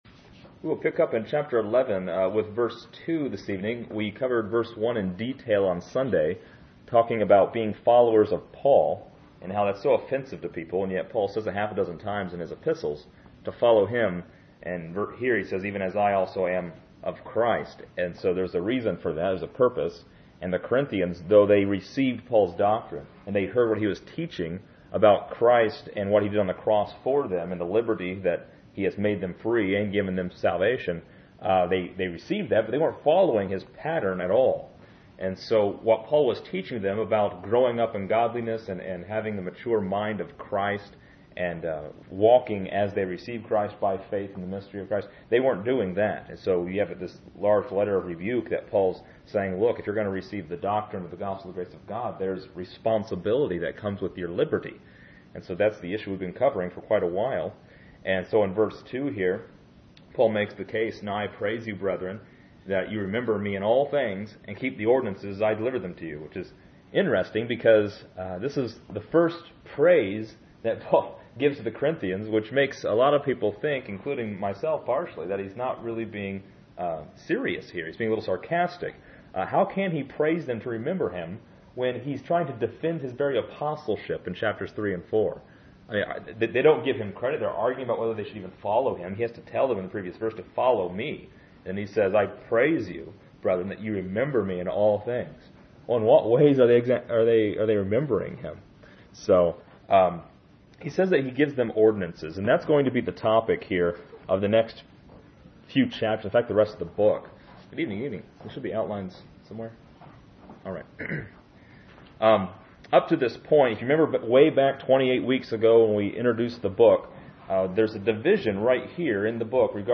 This is a part 28 of a Tuesday night verse by verse study through 1 Corinthians titled Matters of Proper Order.